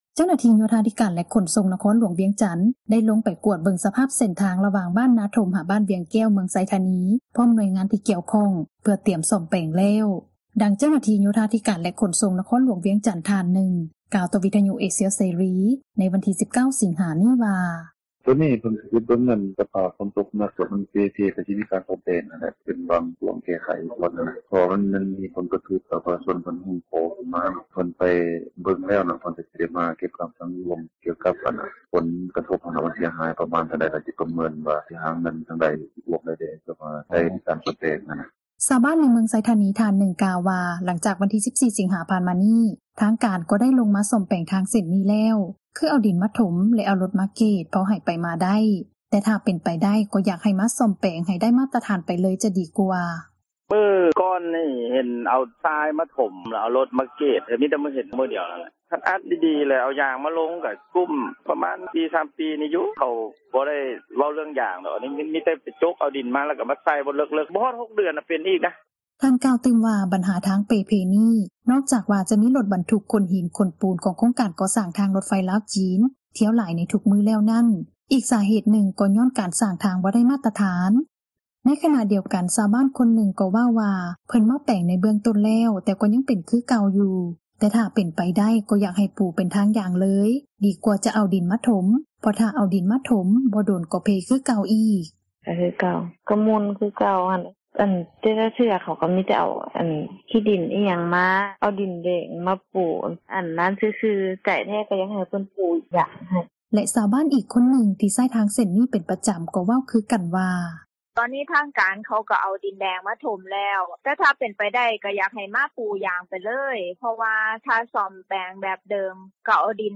ໃນຂນະດຽວກັນ ຊາວບ້ານອີກທ່ານນຶ່ງກໍເວົ້າວ່າ ເພິ່ນມາແປງໃນເບື້ອງຕົ້ນແລ້ວ ແຕ່ກະຍັງເປັນຄືເກົ່າ ຖ້າເປັນໄປໄດ້ກໍຢາກໃຫ້ປູເປັນທາງ ຢາງເລີຍດີກວ່າຈະເອົາດິນມາຖົມ ເພາະຖ້າເອົາດິນມາຖົມ ບໍ່ດົນກໍເພຄືເກົ່າອີກ:
ແລະຊາວບ້ານອີກຄົນນຶ່ງ ທີ່ໃຊ້ທາງເສັ້ນນີ້ ເປັນປະຈໍາ ກໍເວົ້າຄືືກັນວ່າ: